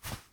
Jump 3.wav